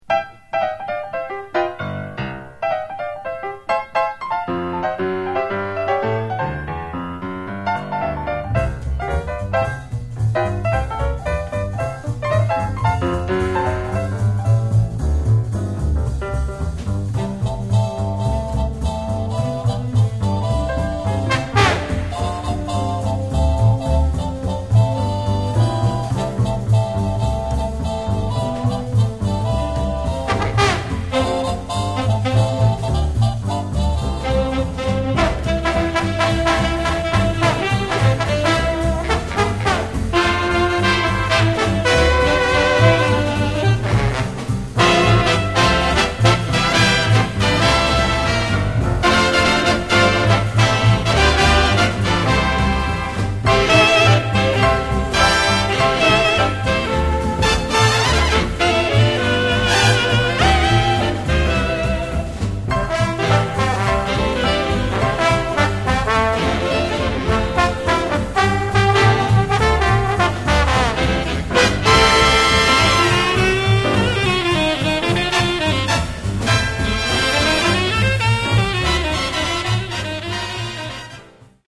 Genre: Christmas
Simply awesome jazz arrangement of a Christmas classic.